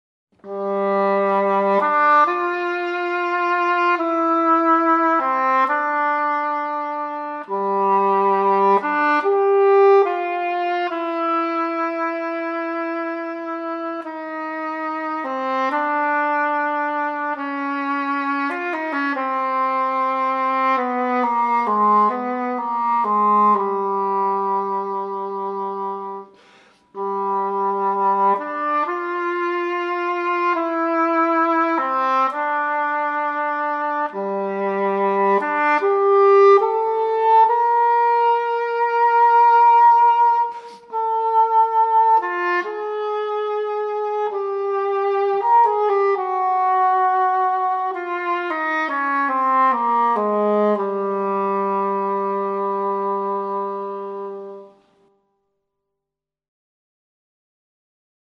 English horn